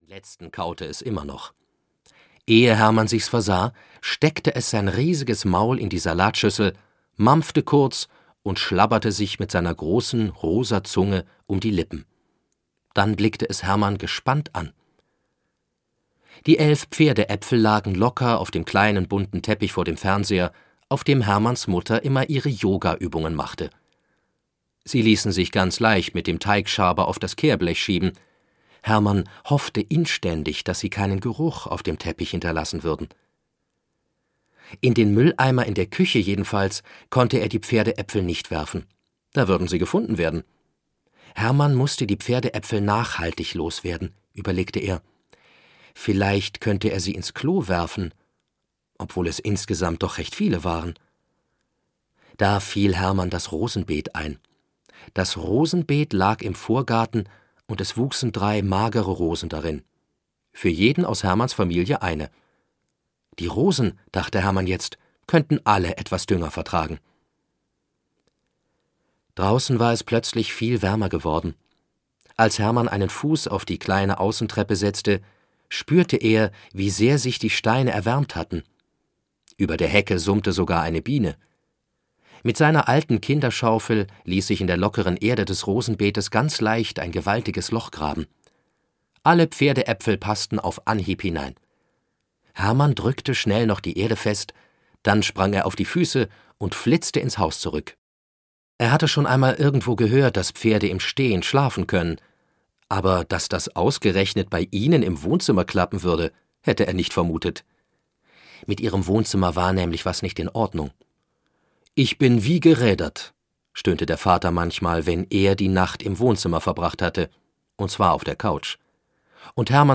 Ein Pferd namens Milchmann - Hilke Rosenboom - Hörbuch